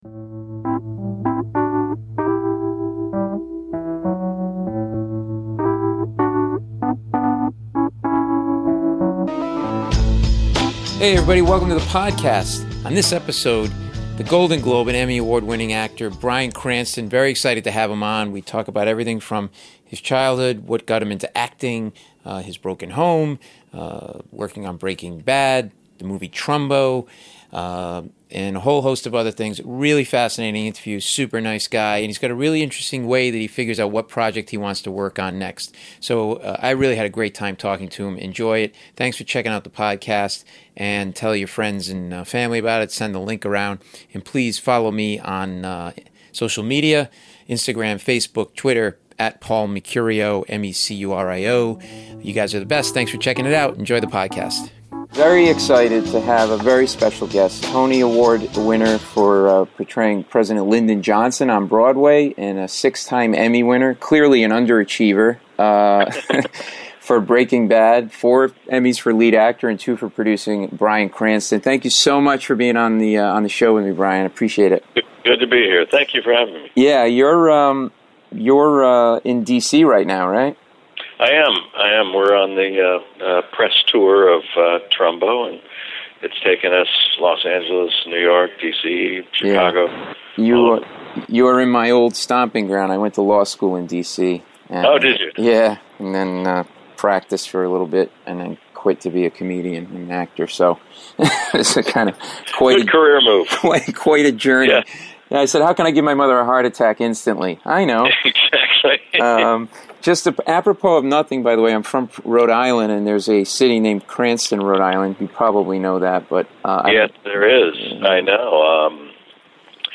A fascinating conversation that gives you a real window into Bryan, not just as an actor, in ways never heard before.